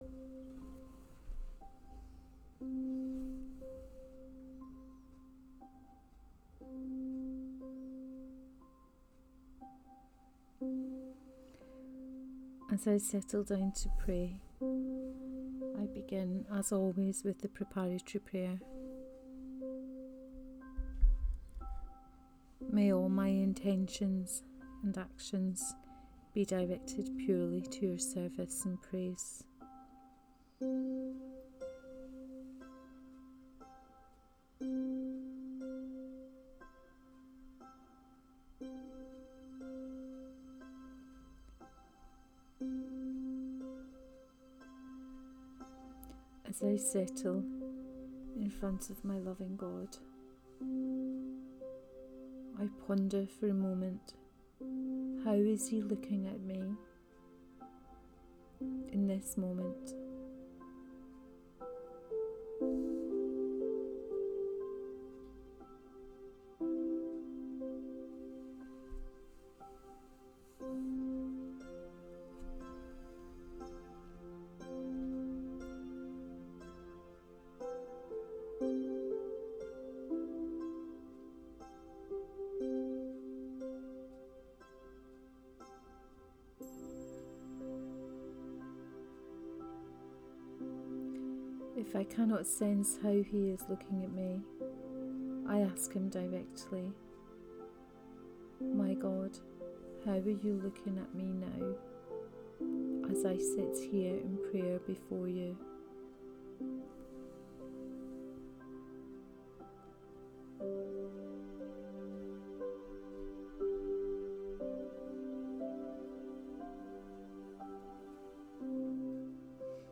Second Sunday in Ordinary Time, Cycle A Here, as stated before, it is my intention to draw from the forthcoming Sunday liturgy and to offer a guided prayer on one of the pieces of scripture in the …